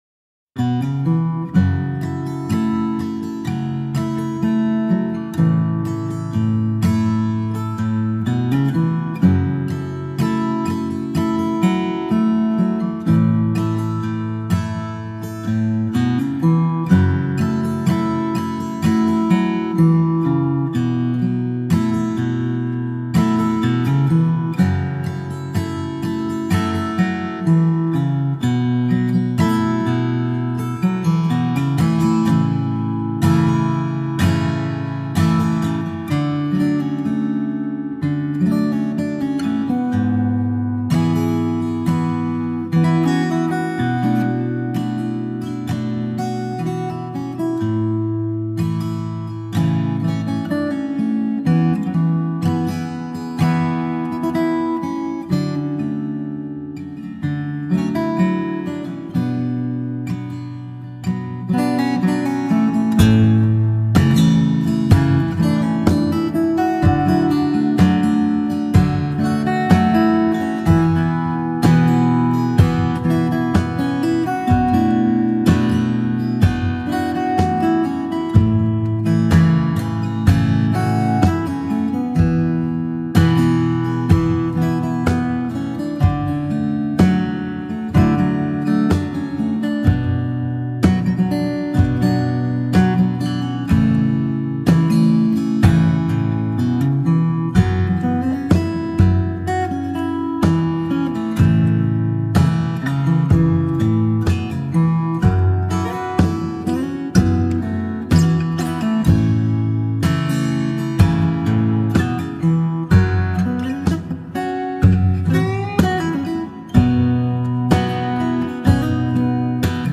Fingerstyle Guitar